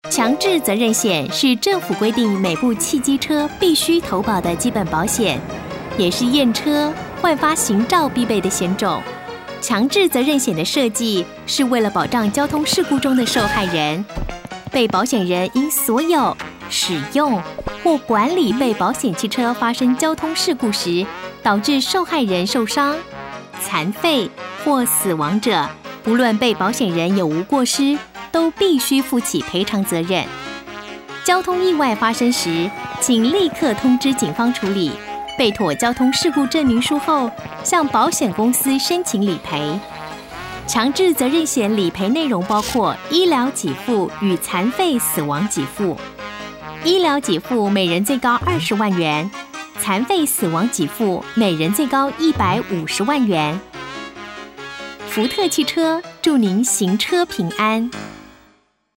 女性配音員
簡介—新聞主播感—福特汽車篇
✔ 聲線沉穩柔和、極具親和力，適合廣告、政府宣導、公部門簡報與品牌行銷影片。
簡介—新聞主播感—福特汽車篇.mp3